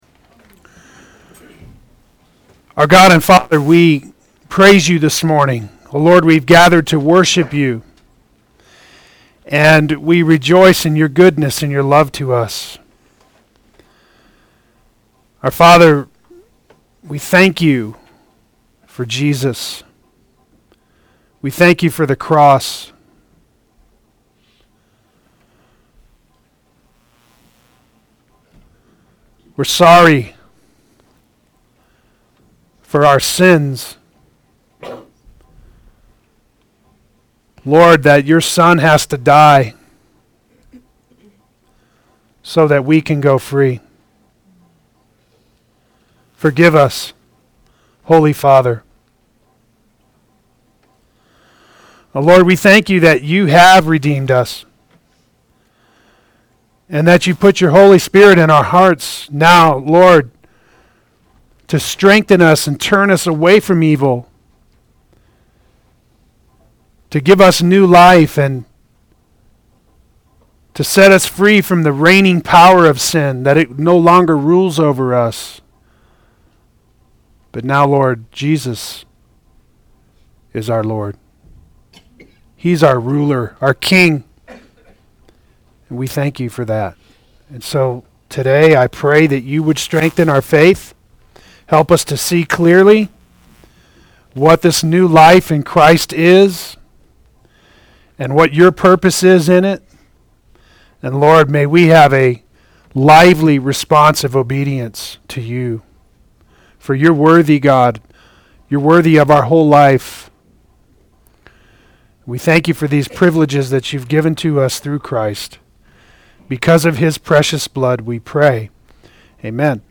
Play Sermon Get HCF Teaching Automatically.
Putting on Christ Adult Sunday School